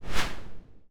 Teleport_Action.wav